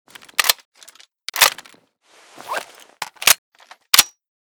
mp5_reload_empty.ogg